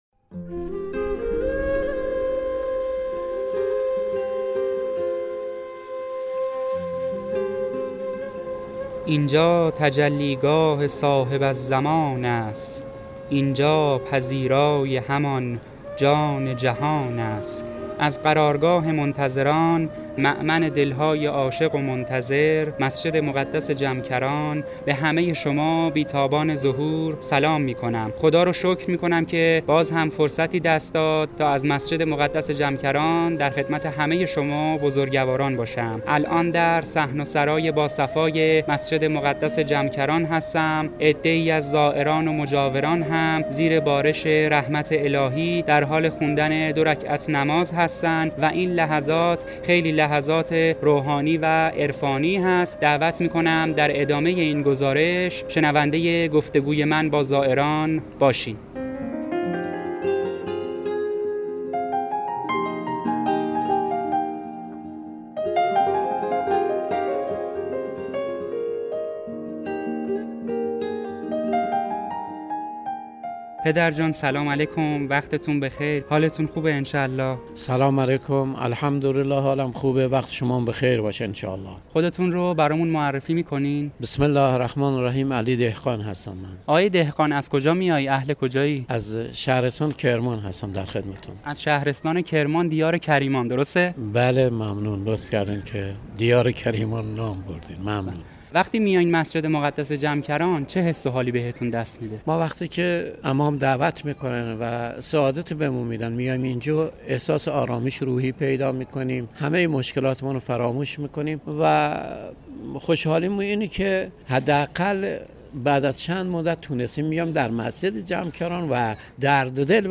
پادکست گزارشی از مسجد مقدس جمکران و گفت و گو با زائران و مجاوران درباره مهدویت و انتظار